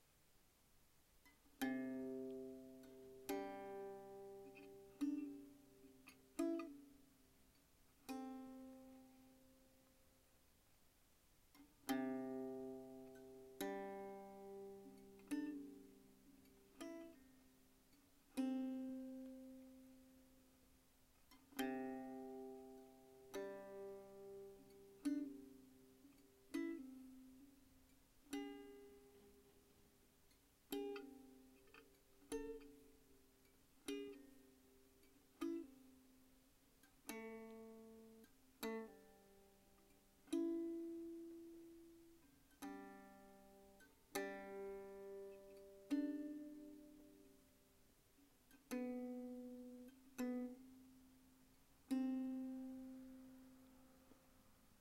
三味線・音源